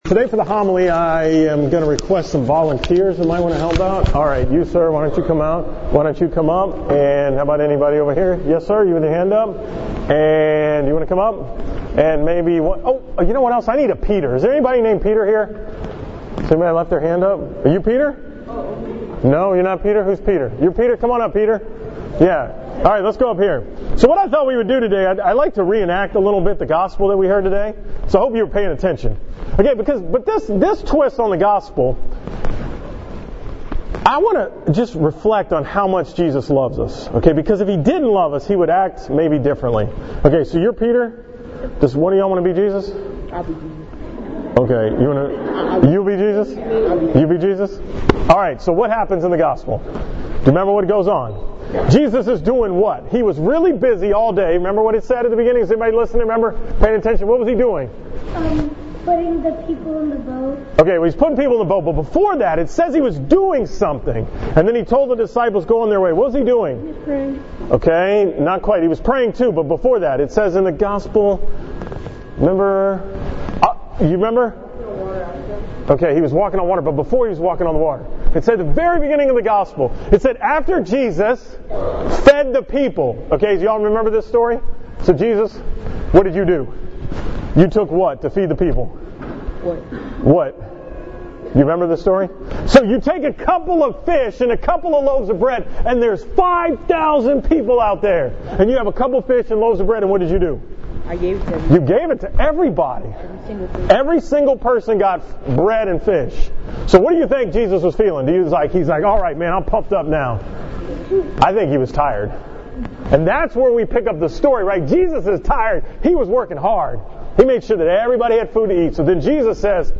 From the School Mass at Regis school on November 17, 2016